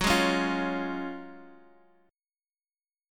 F#M7sus2 chord